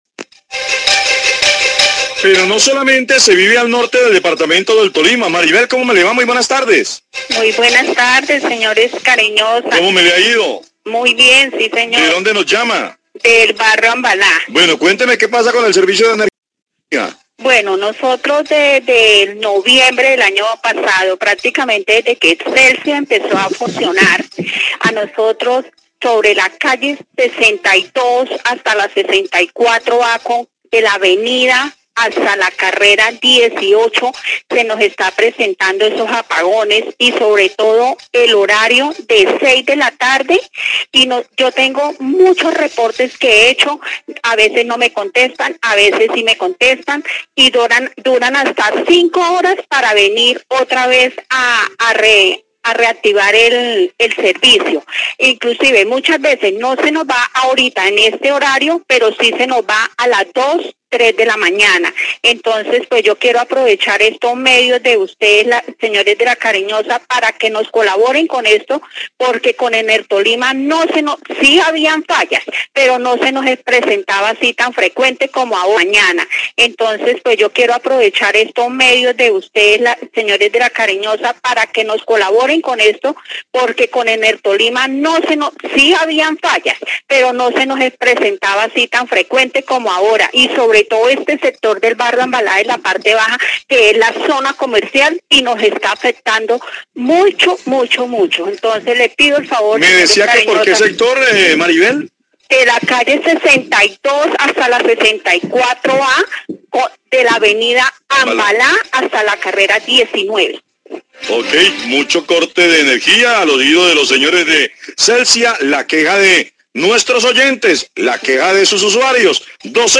Radio
Según una queja teléfonica, los habitantes del barrio Ambalá en la ciudad de Ibagué se encuentran inconformes con los continuos cortes de energía y la falta de agilidad de Celsia para resolverlos, dicen que la situación ha empeorado tras la salida de Enertolima de la región y la llegada de Celsia.